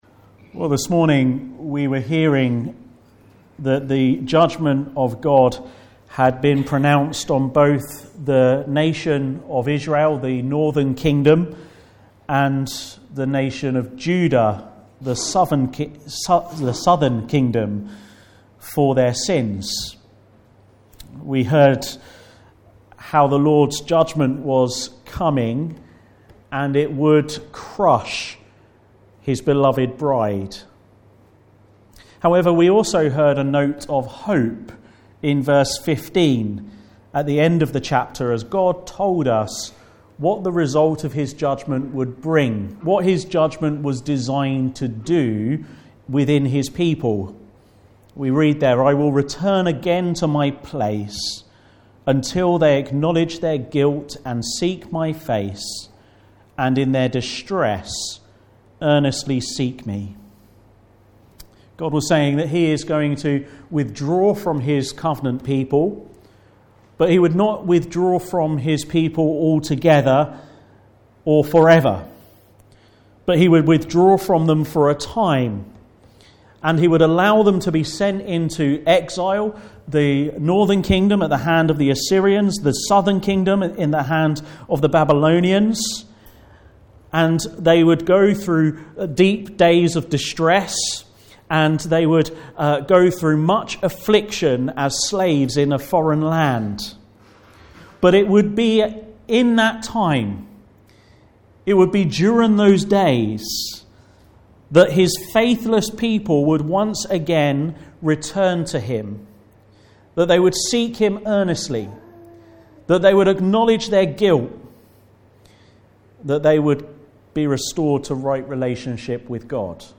5-10 Service Type: Afternoon Service Special Service Did Jesus Really Rise From the Dead?